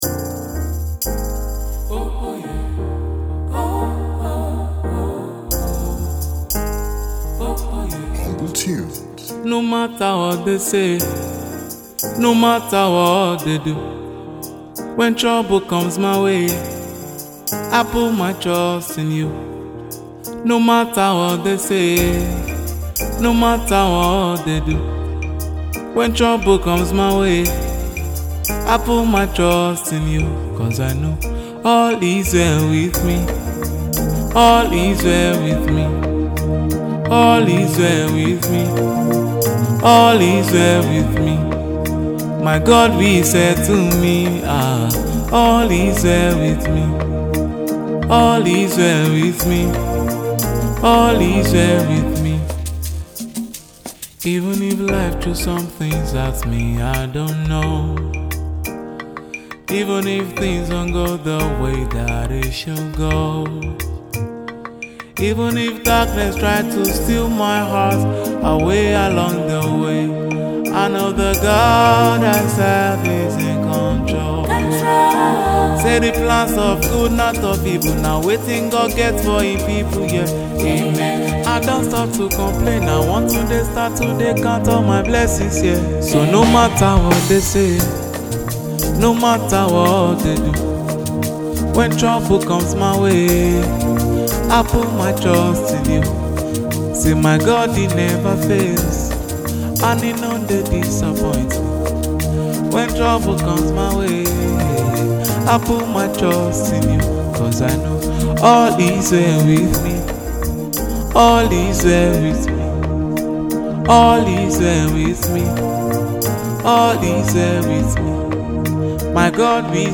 Lagos-based Nigerian gospel singer